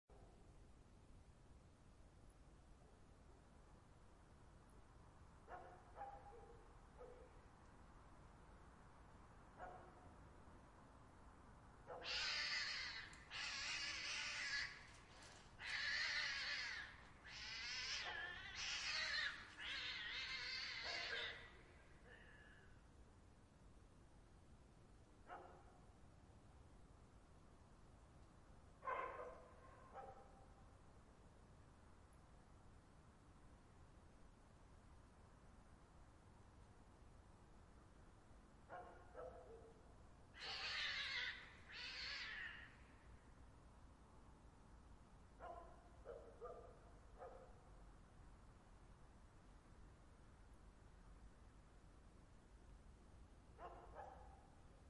Sound Effects
Cat Fight